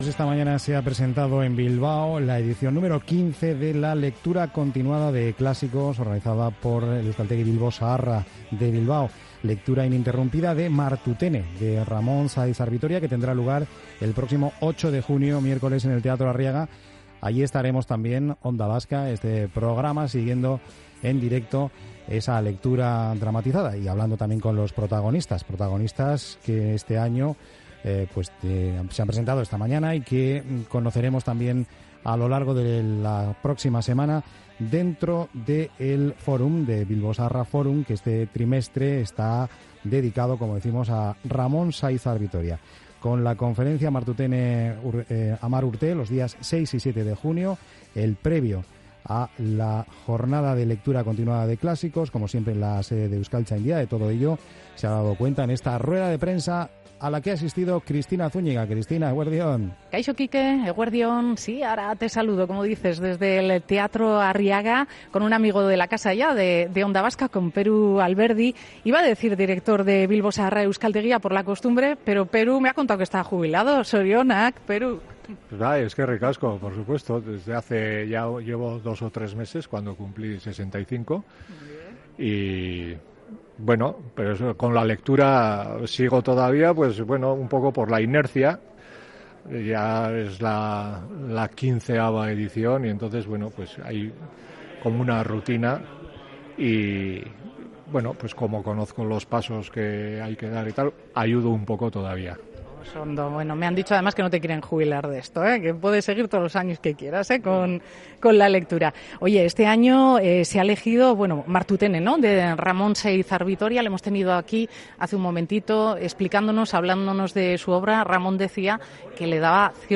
Charlamos con